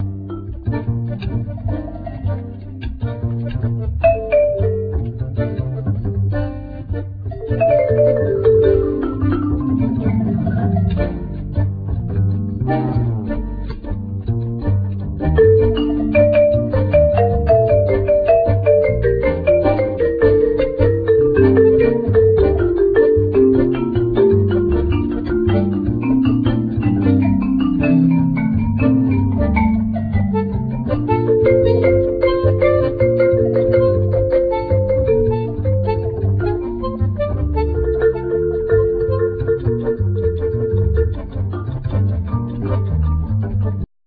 Bandneon,Voice,Percussion
Acoustic & Electric Bass
Marimba,Vibraphone,Percussion